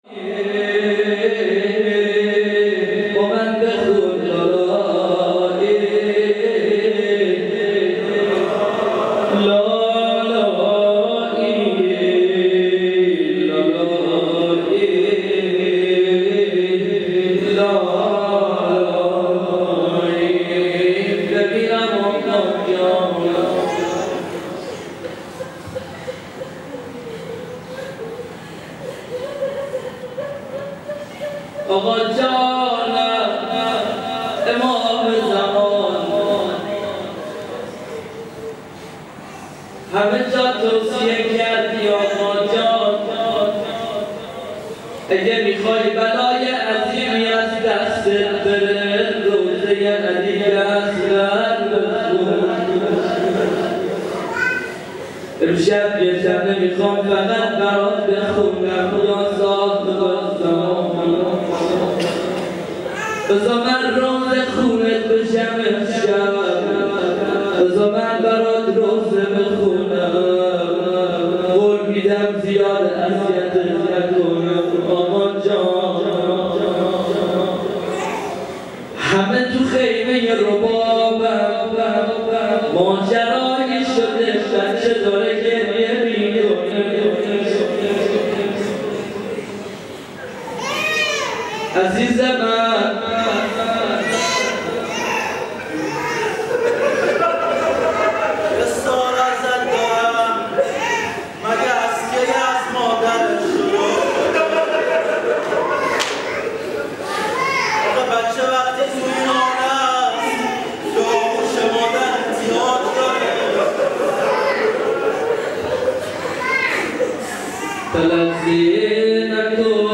روضه | با من بخون لالایی
شب هفتم محرم الحرام - 1439 هـ | هیأت علی اکبر بحرین